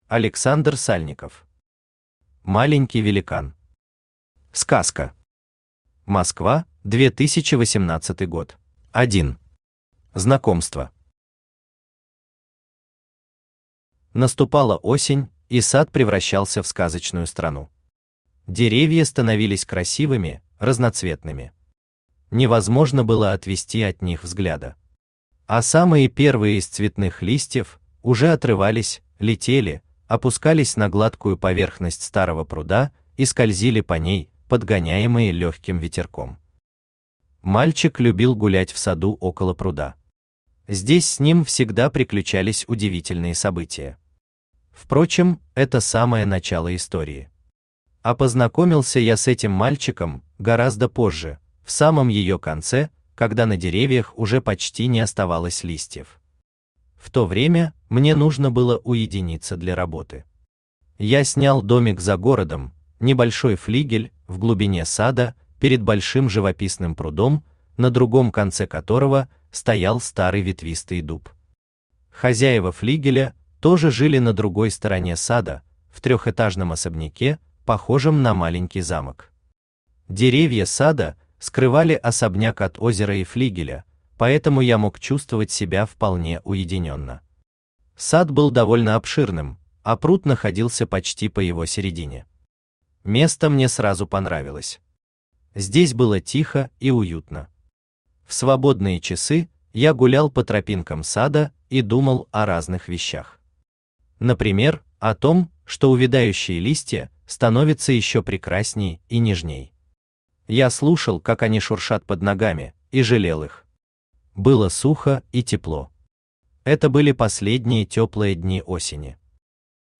Аудиокнига Маленький великан | Библиотека аудиокниг
Aудиокнига Маленький великан Автор Александр Аркадьевич Сальников Читает аудиокнигу Авточтец ЛитРес.